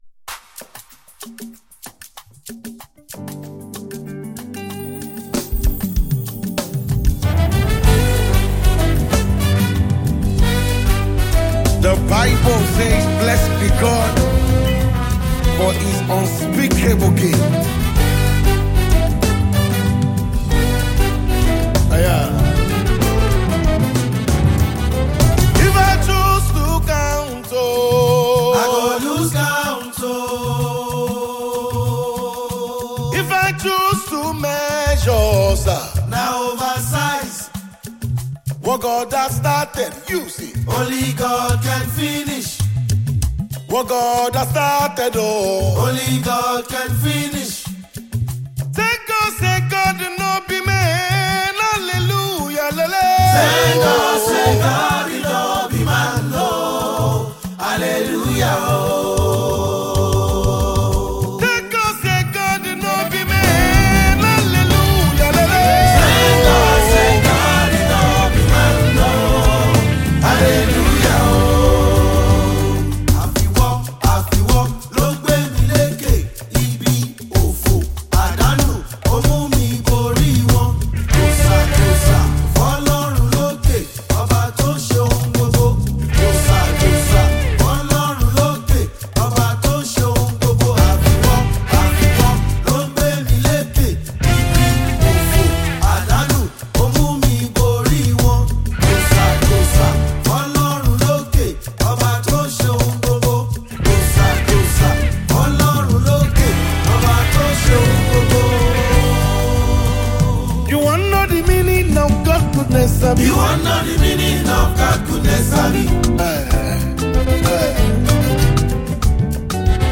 Nigerian gospel minister and dynamic praise leader
praise medley